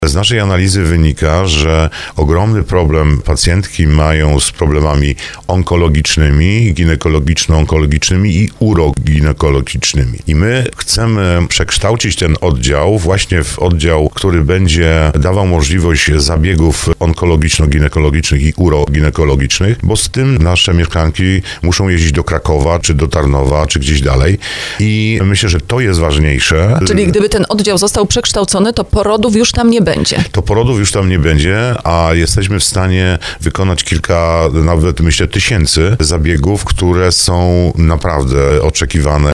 Jak mówił na antenie RDN Małopolska starosta brzeski Andrzej Potępa, to właśnie na taki oddział jest w regionie ogromne zapotrzebowanie.